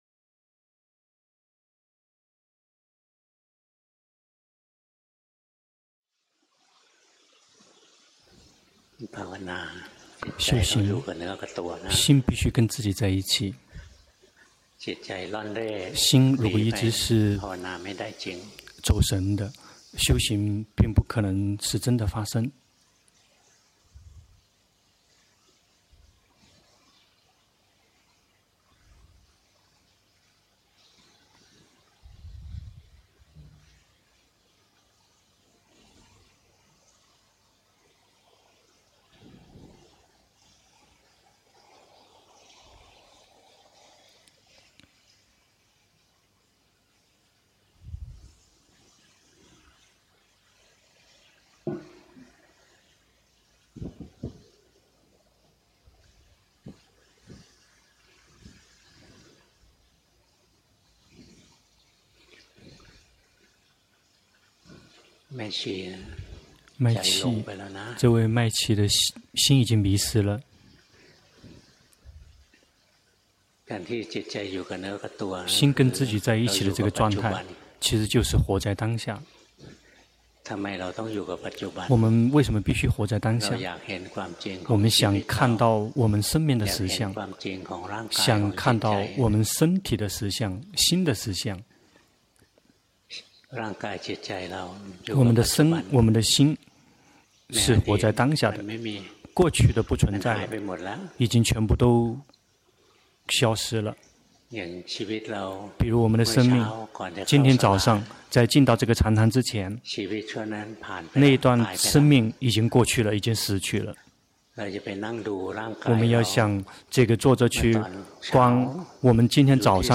法談摘錄
泰國解脫園寺